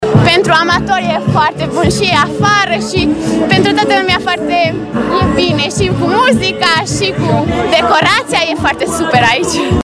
La inaugurare au participat și sportivi de la Clubul Sportiv Miercurea Ciuc.